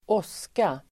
Uttal: [²'ås:ka]